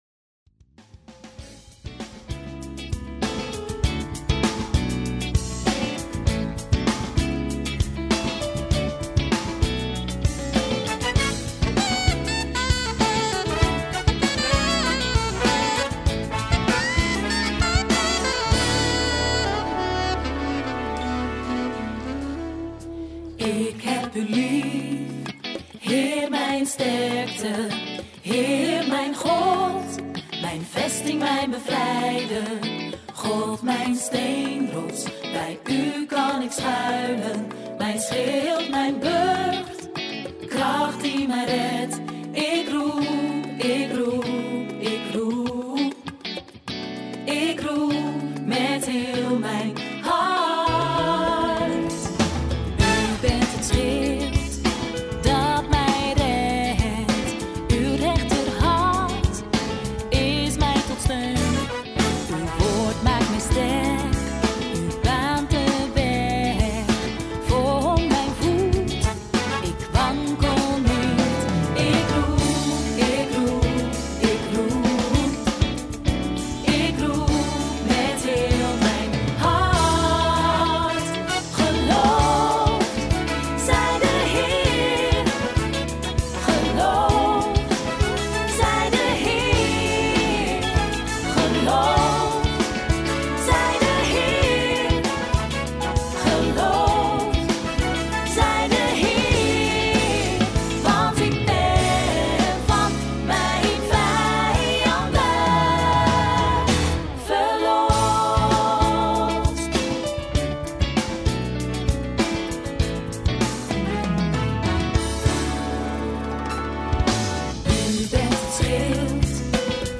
Gospel Pop, Praise & Worship